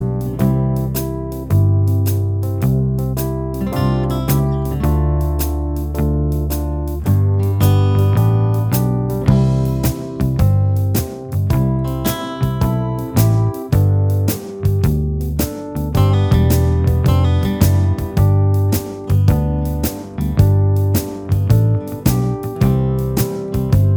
Minus Lead Guitar Pop (1970s) 3:29 Buy £1.50